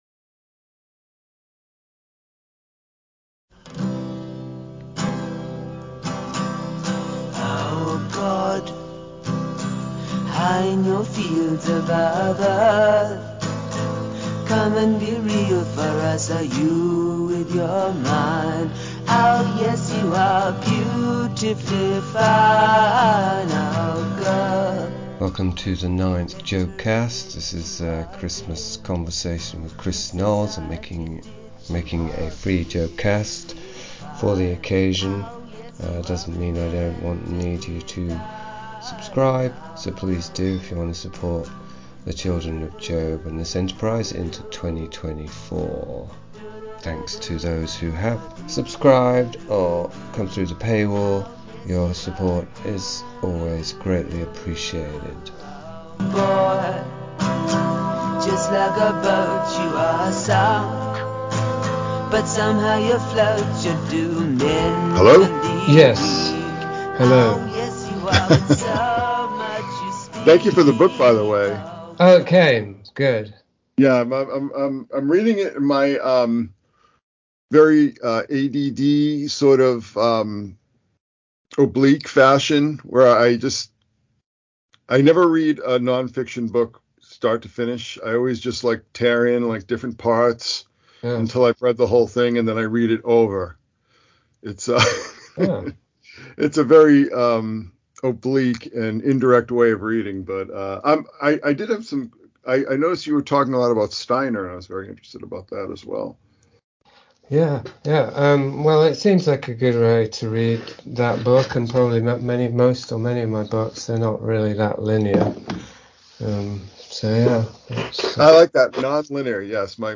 Audio file without music breaks.